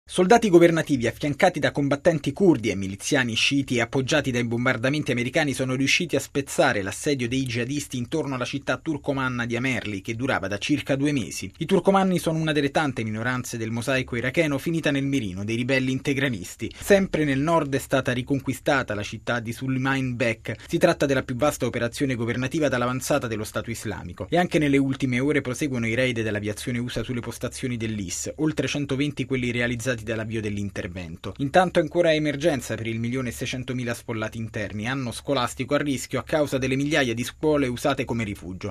Bollettino Radiogiornale del 01/09/2014